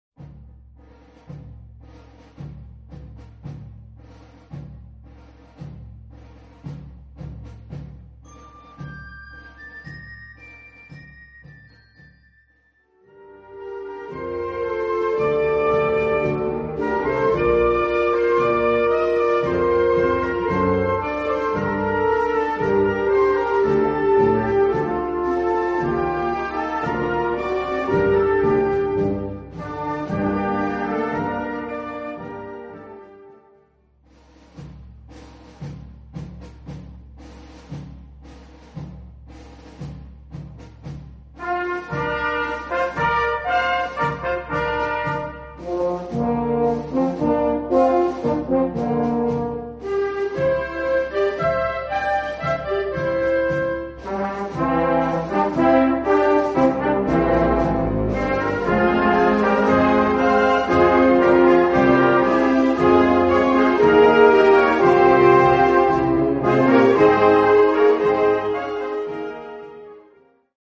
Gattung: Weihnachtliche Blasmusik
Besetzung: Blasorchester